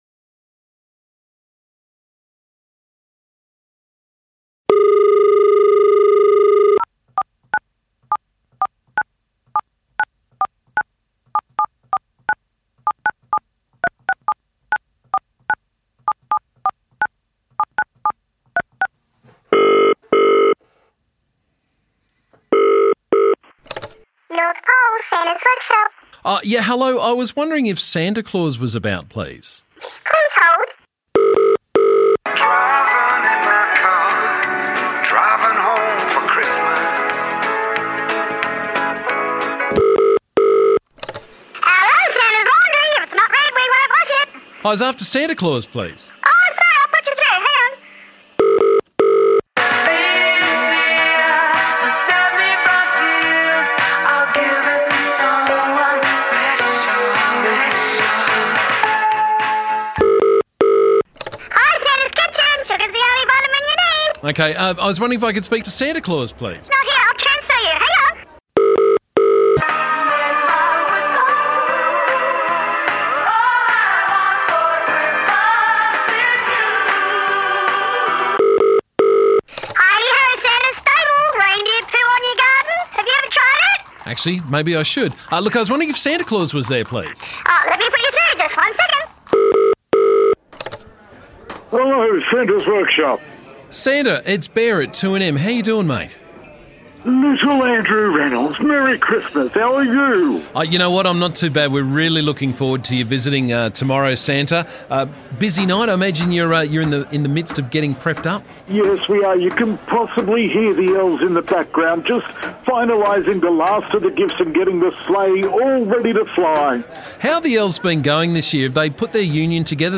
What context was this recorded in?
This morning we gave Santa Claus a call in his workshop to find out how preparations are coming along and to find out what happens after he visits the Upper Hunter.